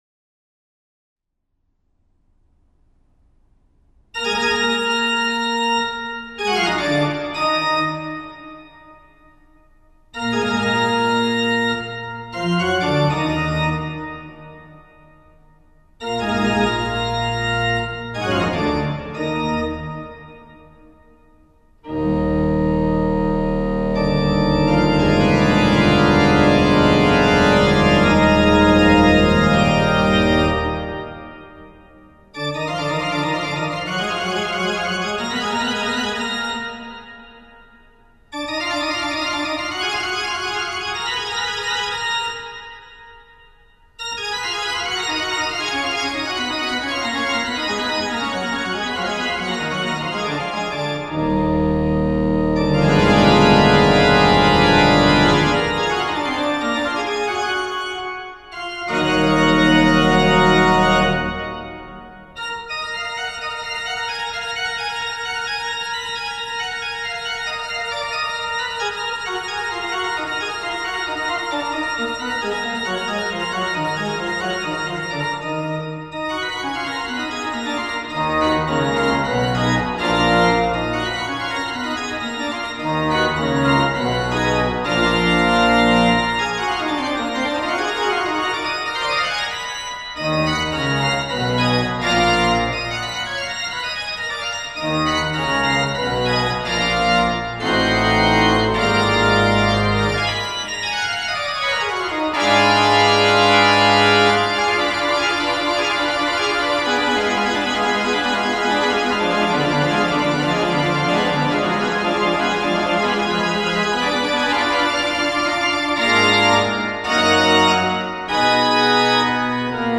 音樂類型 :管風琴